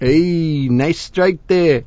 l_ehhnicestrikedere.wav